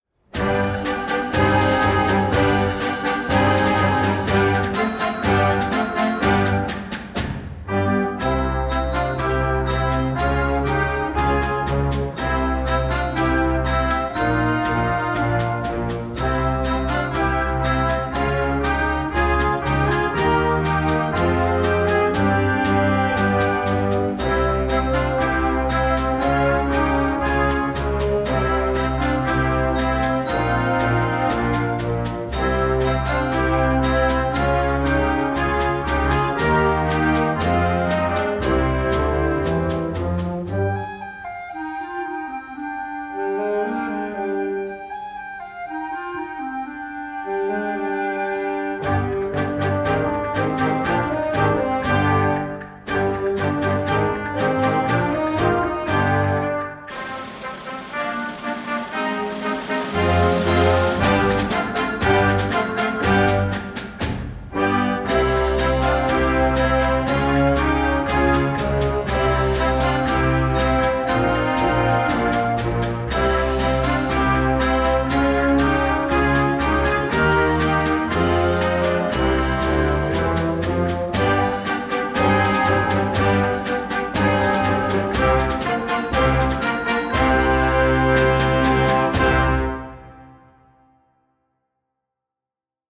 Gattung: Konzertwerk für Jugendblasorchester
Besetzung: Blasorchester
energiegeladenen, spanisch angehauchten Stück